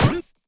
pgs/Assets/Audio/Sci-Fi Weapons/sci-fi_weapon_laser_small_fun_02.wav at master
sci-fi_weapon_laser_small_fun_02.wav